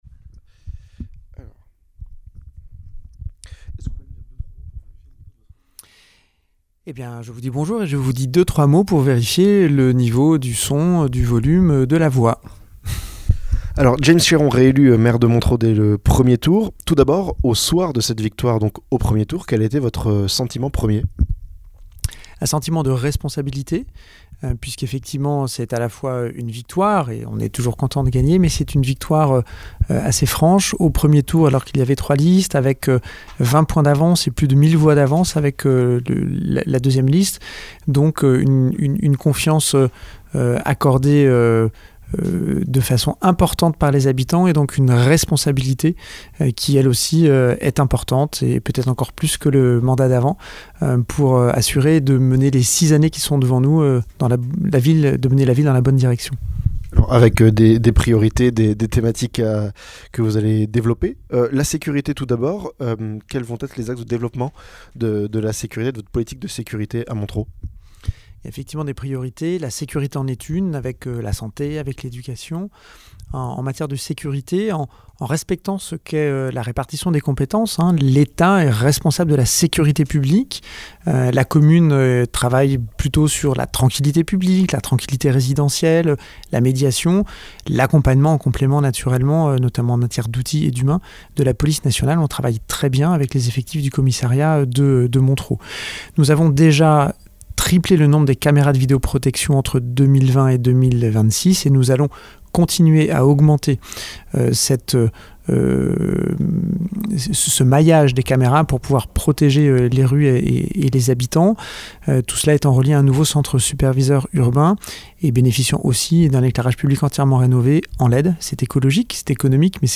ENTRETIEN - James Chéron, maire de Montereau et nouveau président du Pays de Montereau
Entretien exclusif avec James Chéron, réélu maire de Montereau en mars et élu Président du Pays de Montereau lundi soir.